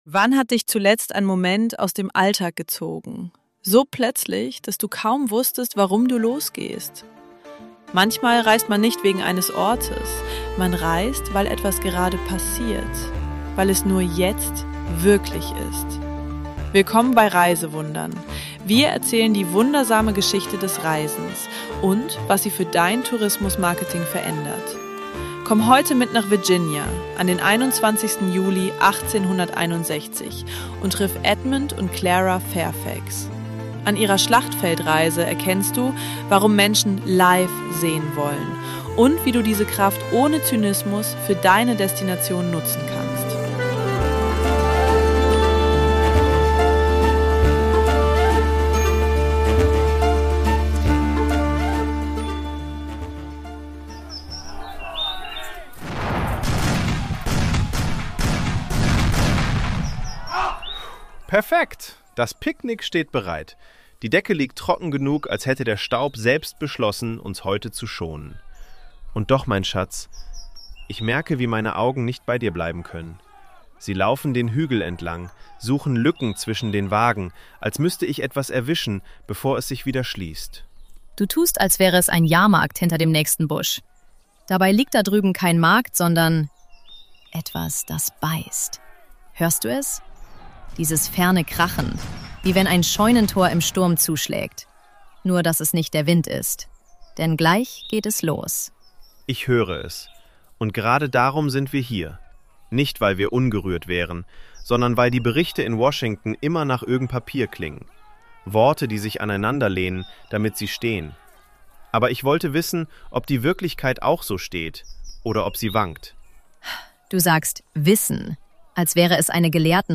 Die Episode über Schlachtfeld-Reisen beleuchtet eine historisch belegte Ausflugspraktik rund um die erste Schlacht von Bull Run (Manassas): Zivilpersonen aus Washington, D.C. reisen mit Kutsche, Wagen oder zu Fuß zu Aussichtspunkten nahe des Geschehens – mit Picknickkorb und Opernglas, und geraten beim Rückweg in die Bewegungen der zurückweichenden Truppen. Im Dialog der Fairfax wird sichtbar, wie Nähe zur Wirklichkeit zugleich fasziniert und beschämt: Das Bedürfnis, Gerüchte durch eigene Wahrnehmung zu ersetzen, kippt leicht in Zynismus, wenn das „Live-Dabeisein“ zum Selbstzweck wird.